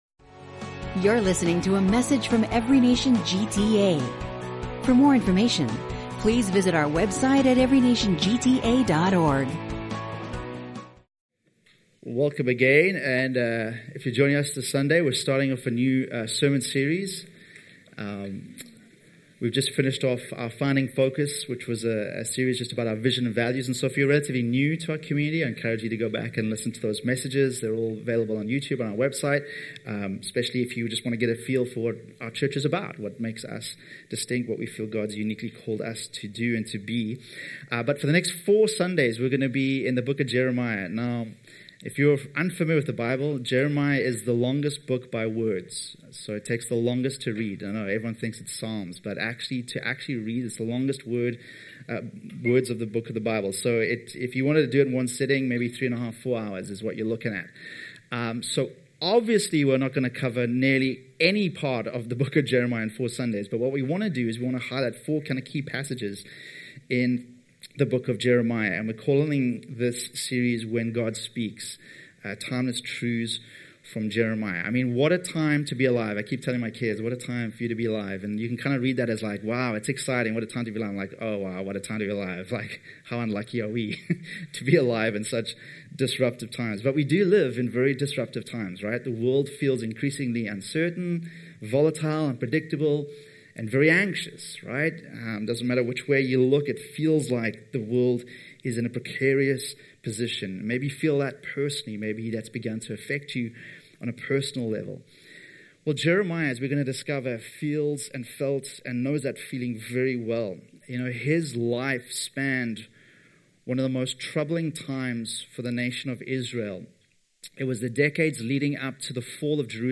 This sermon explores the prophetic call through the lens of Jeremiah's ministry, drawing parallels to Jesus' life and the modern Christian's role. It emphasizes the importance of being a faithful presence in the world, confronting reality while maintaining hope, and embodying both grace and truth in daily life.